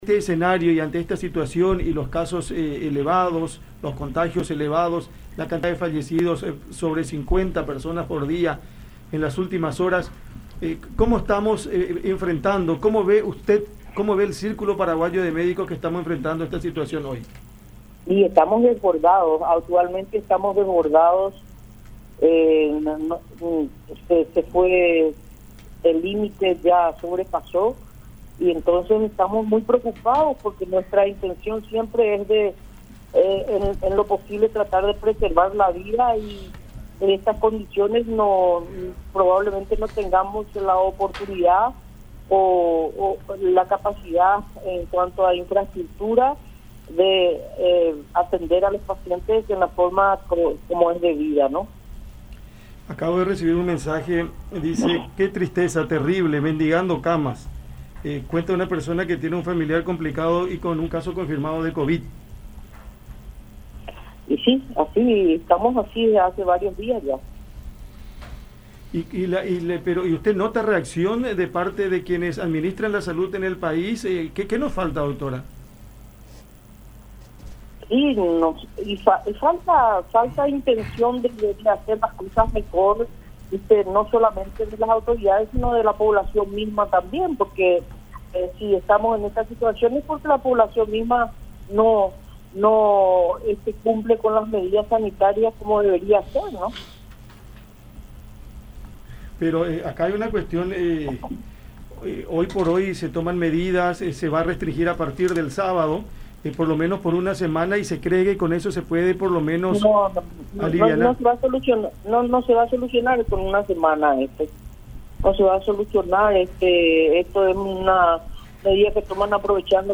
en diálogo con La Unión.